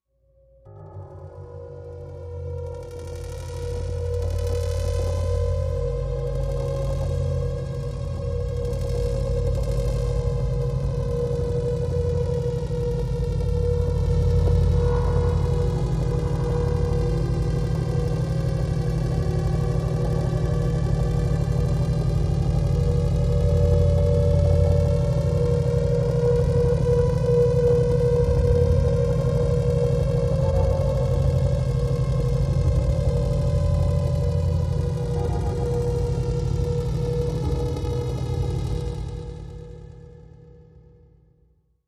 Gravity Flux Slow Moving Electro Static Pulses Vibration